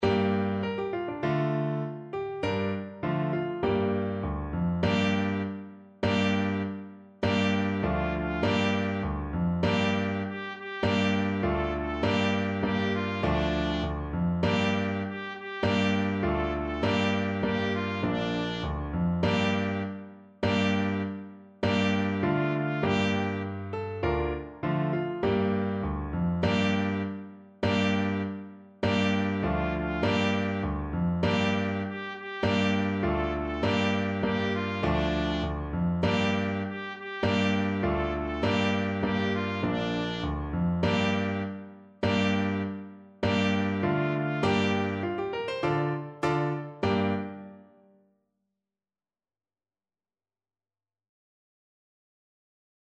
Trumpet
2/4 (View more 2/4 Music)
Firmly =c.100
D5-A5
G minor (Sounding Pitch) A minor (Trumpet in Bb) (View more G minor Music for Trumpet )
Traditional (View more Traditional Trumpet Music)
world (View more world Trumpet Music)